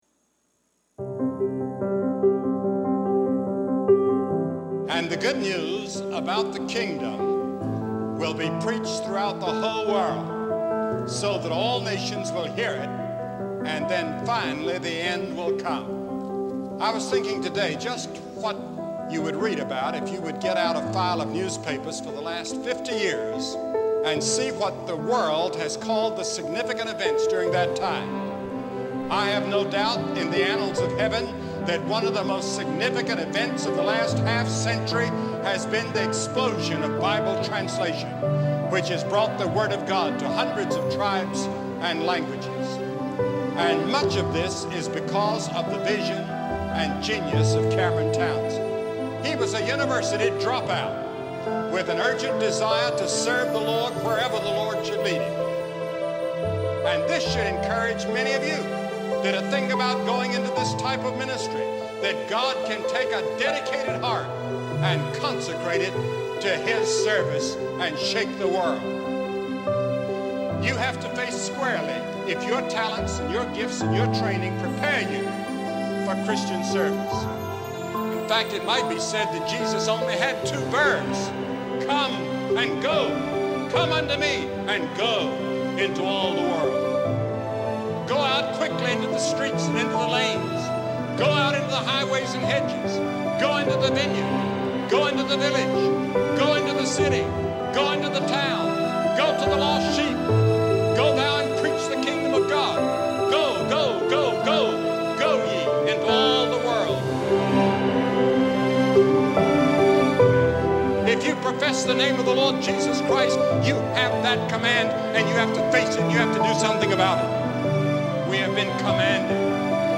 Wycliffe Presentation.mp3